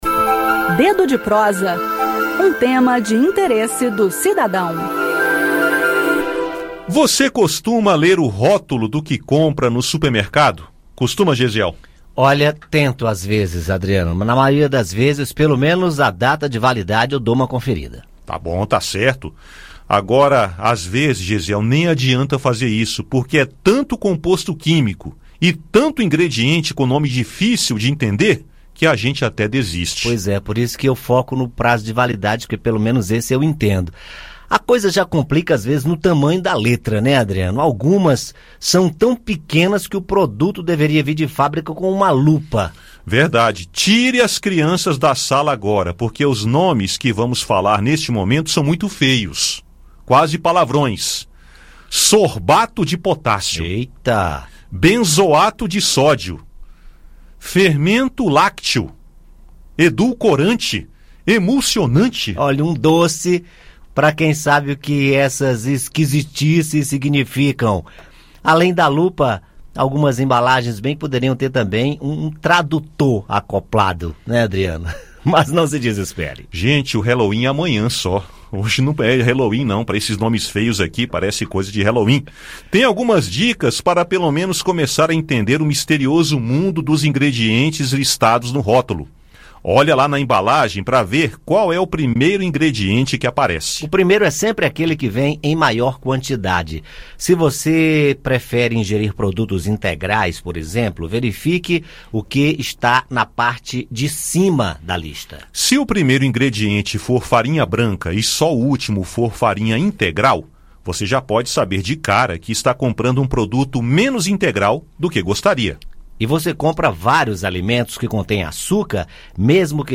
E o assunto do "Dedo de Prosa" desta quarta-feira (30) são as informações sobre os ingredientes e as substâncias químicas que vêm especificadas nos rótulos dos alimentos industrializados. Ouça o áudio com o bate-papo.